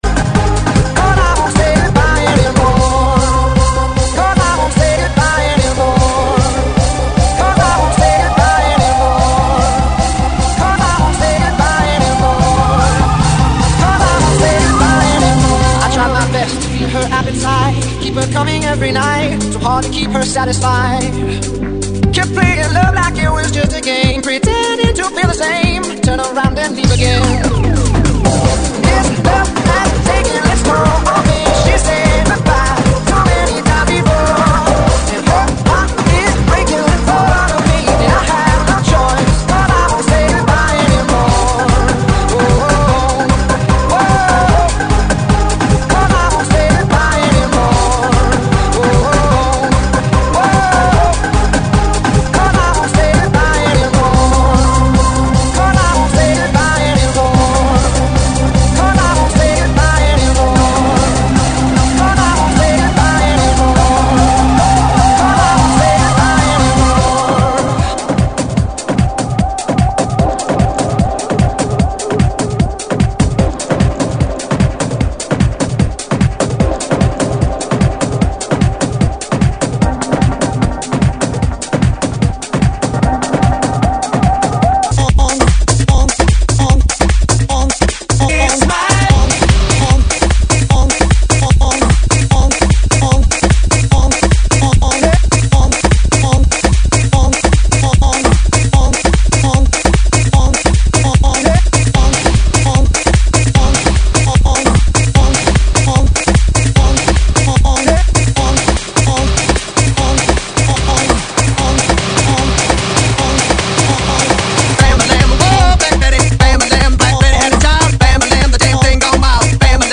GENERO: POP – INGLES – RETRO